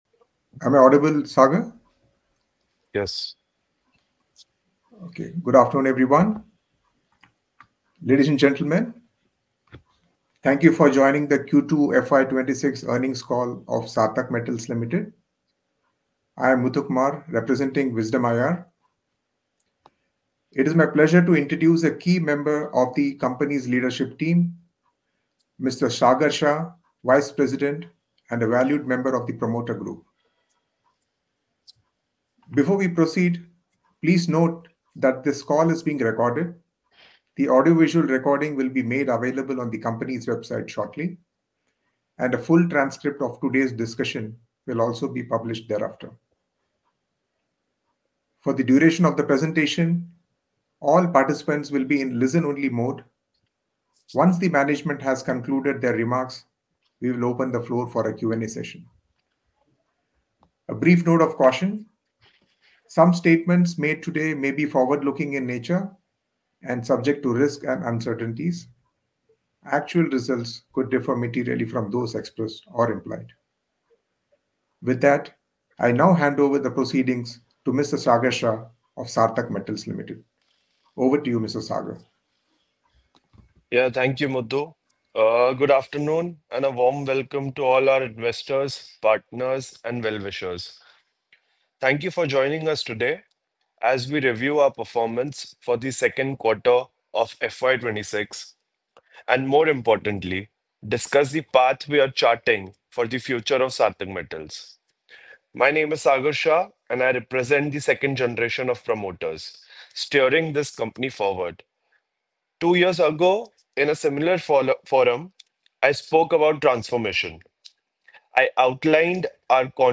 » Earnings Call for Q2 FY 25-26 - Audio | Audio link